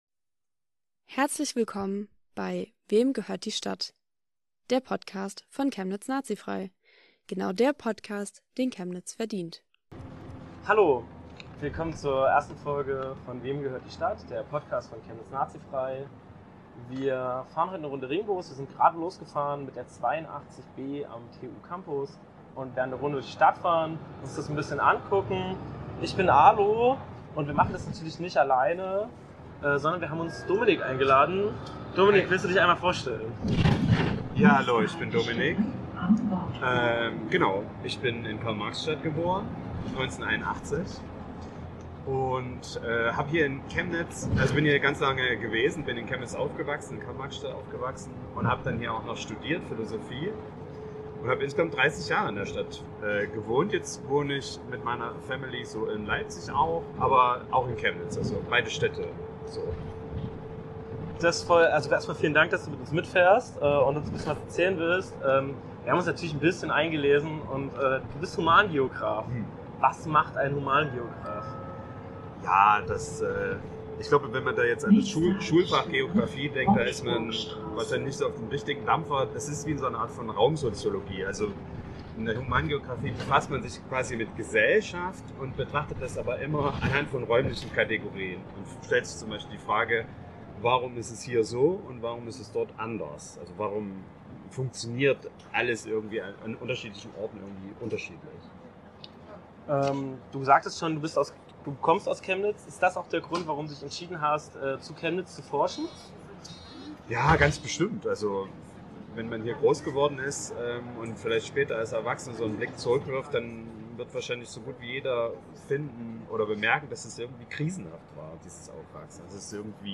In Folge 1 steigen wir in den Ringbus 82 und fahren einmal durch Chemnitz.